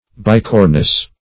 Search Result for " bicornous" : The Collaborative International Dictionary of English v.0.48: Bicorn \Bi"corn\, Bicorned \Bi"corned\, Bicornous \Bi*cor"nous\, a. [L. bicornis; bis twice + cornu horn: cf. F. bicorne.